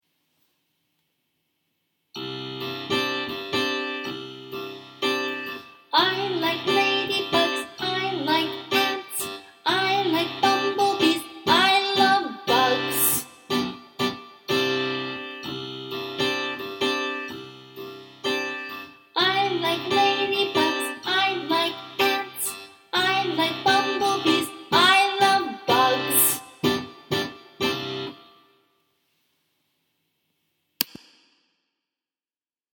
Here’s a simple pattern to create a song or chant.
You can use so and mi for this song, too.